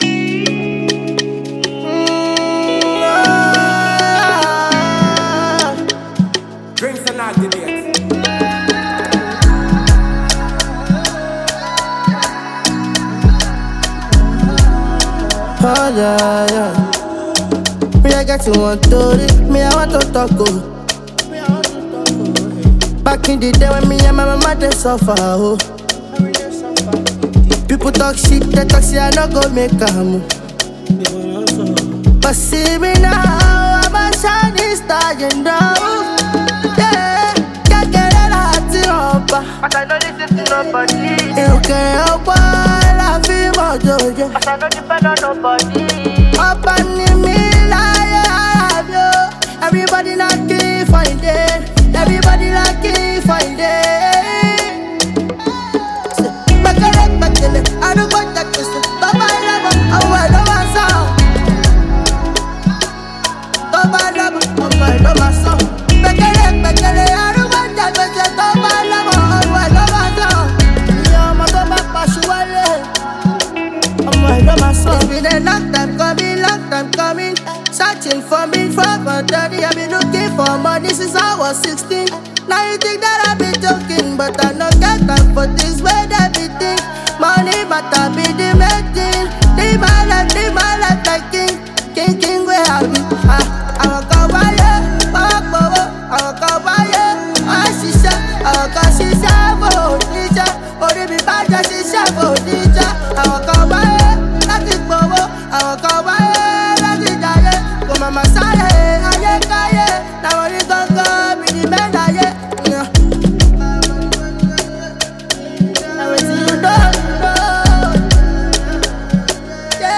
Vocal talented singer
Afrobeats
The melodious Afrobeats tune